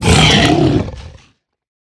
Minecraft Version Minecraft Version snapshot Latest Release | Latest Snapshot snapshot / assets / minecraft / sounds / mob / hoglin / death3.ogg Compare With Compare With Latest Release | Latest Snapshot